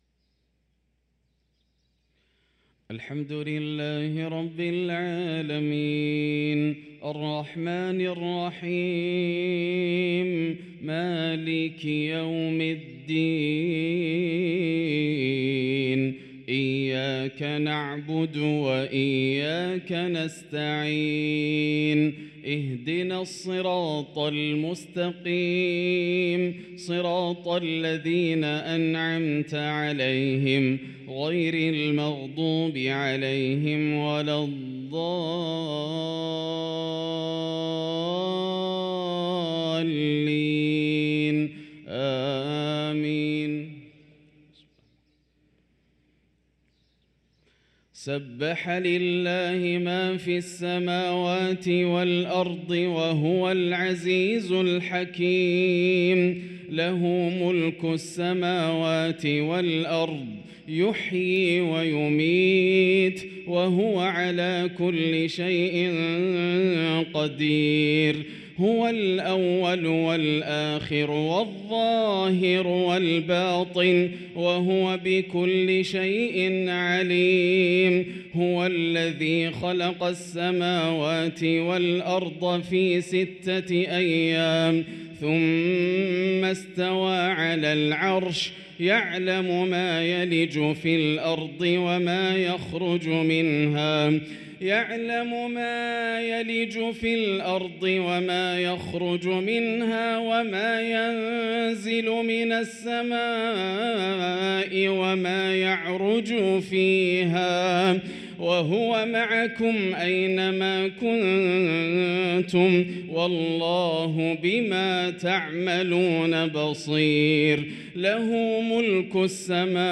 صلاة الفجر للقارئ ياسر الدوسري 8 صفر 1445 هـ
تِلَاوَات الْحَرَمَيْن .